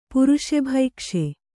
♪ puruṣa bhaikṣe